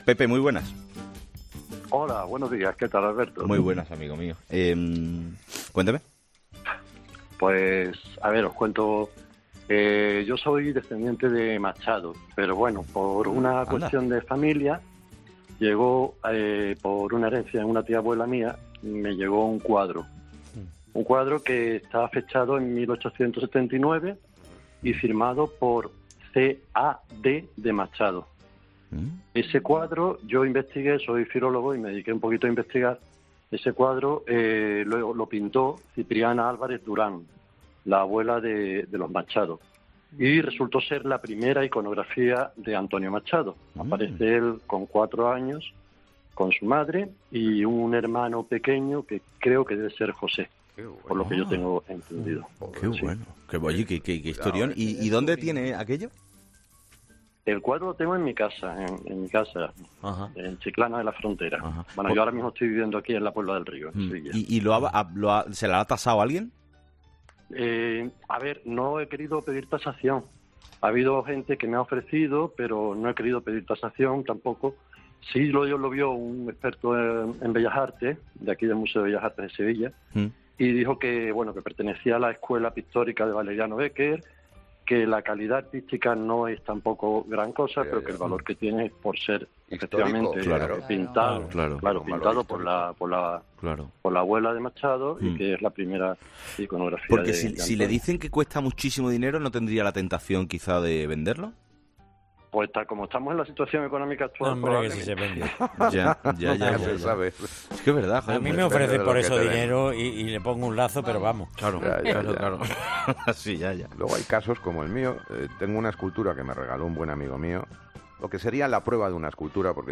Un oyente de Chiclana asegura que heredó un retrato histórico de Antonio Machado